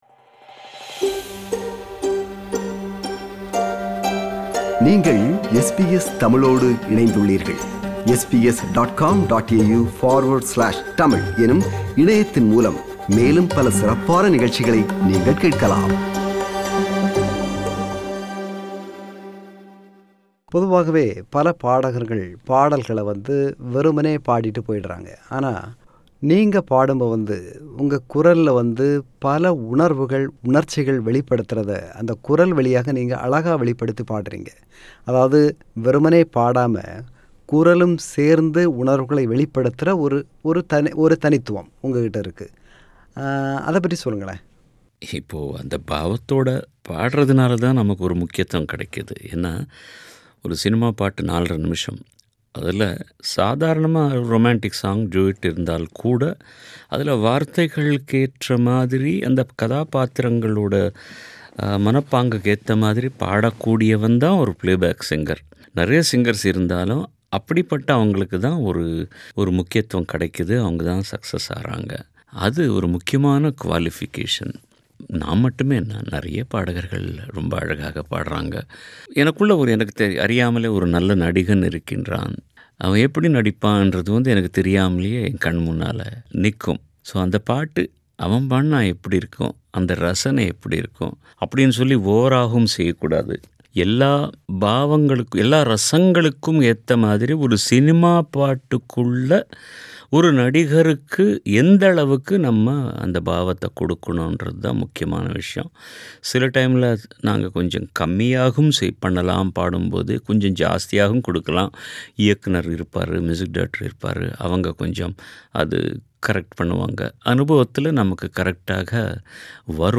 மறைந்த பிரபல பின்னணிப்பாடகர் S P பாலசுப்ரமணியம் அவர்கள் SBS தமிழ் ஒலிபரப்புக்கு சுமார் ஆறு ஆண்டுகளுக்கு முன்பு வழங்கிய நேர்முகத்தின் மறுபதிவு.